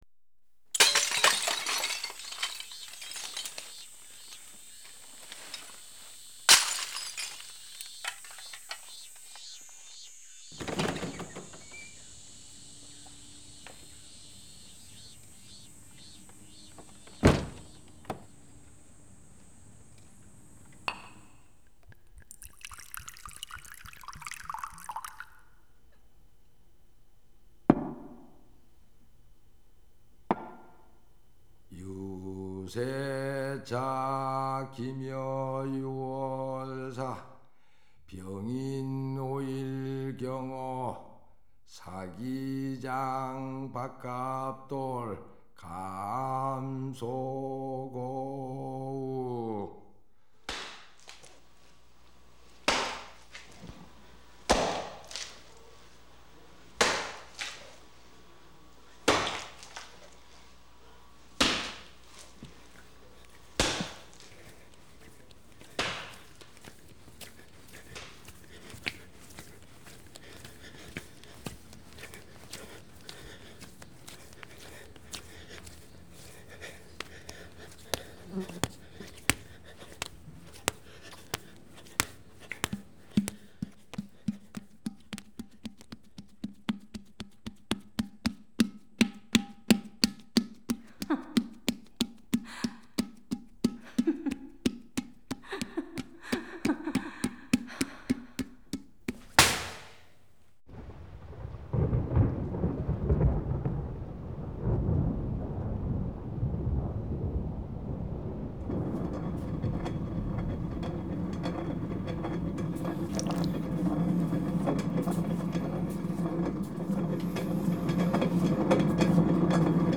음향다큐멘터리와 구체음악
명품 도자기를 만들려는 도공의 집념, 그 끝없는 도전을 도자기 만드는 소리로 구성하기로 했지요.
고작 4분 30초 길이의 오디오 프로그램을 한 달쯤 현장 녹음하고, 석 달이나 매달려 겨우 만들어 냈습니다.
익숙하지 않은 소리를 들으면 무슨 소리인지 알아듣지 못합니다.
1999년, 한 MBC FM프로그램 안에 코너 형식으로 이 프로그램을 방송했습니다.